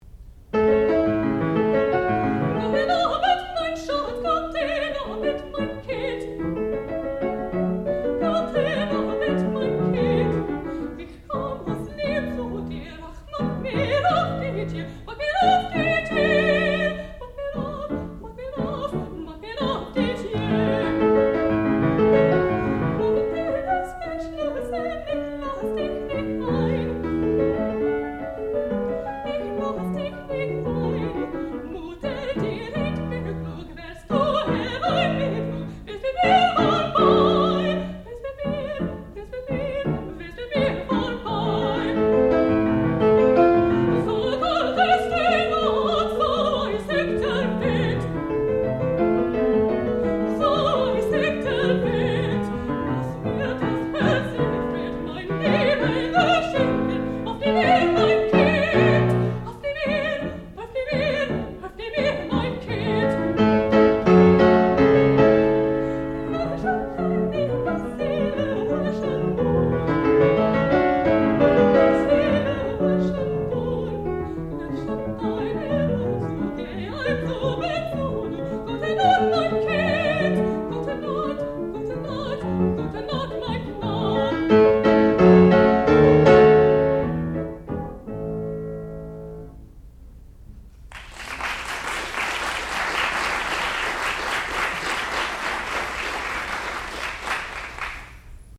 sound recording-musical
classical music
Qualifying Recital